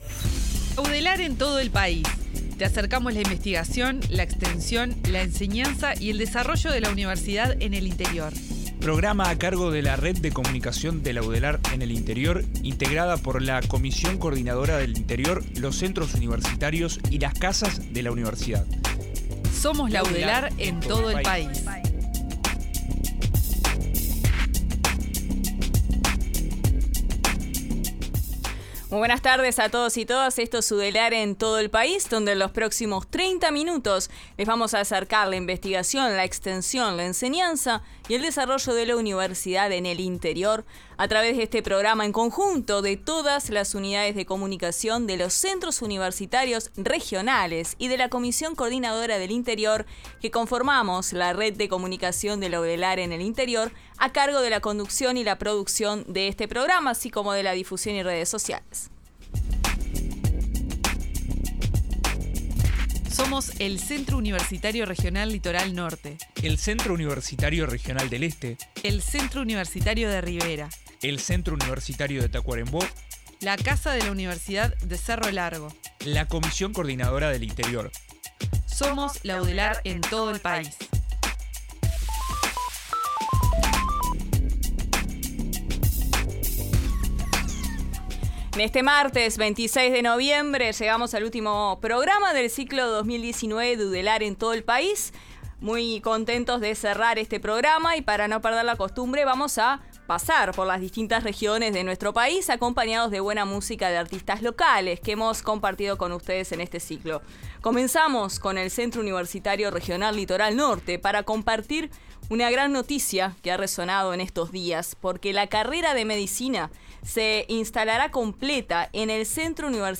Y como siempre la música de los artistas locales.